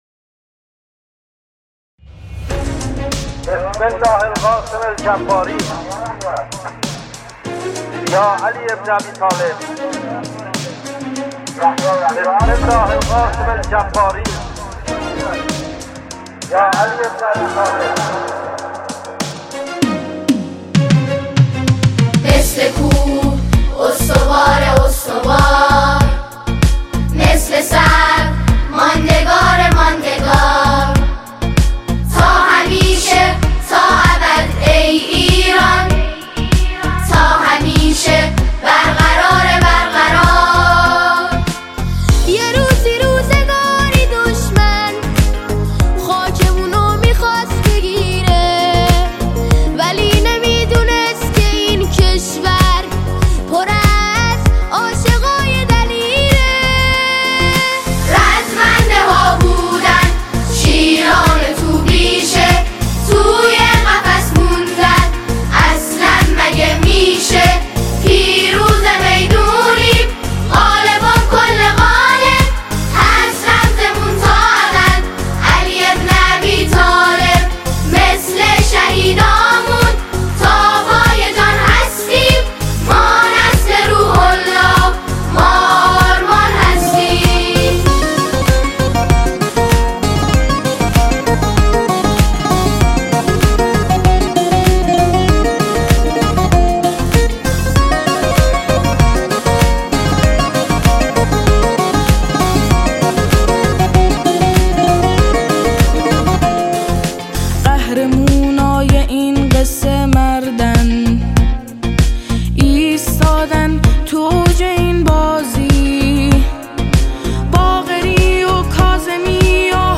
سرود پرشور و حماسی
با زبانی کوبنده و ریتمی میدانی
ژانر: سرود